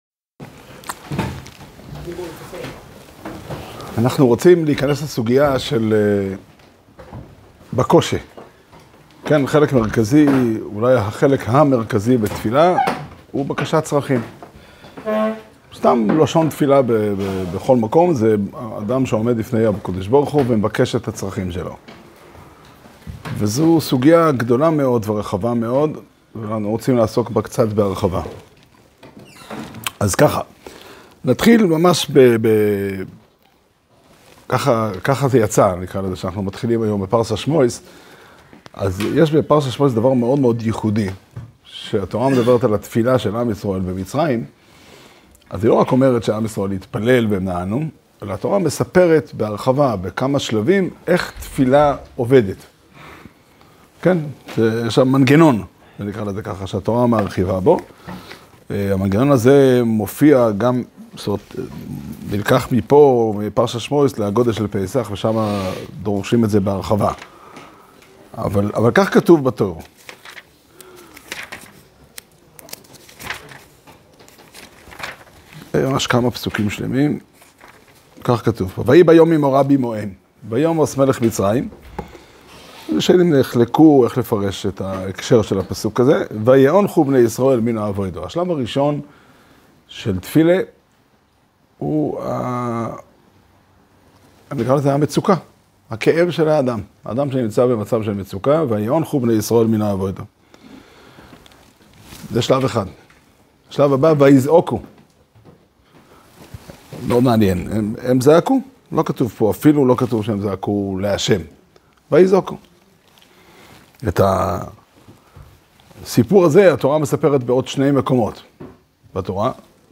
שיעור שנמסר בבית המדרש פתחי עולם בתאריך כ"ג טבת תשפ"ה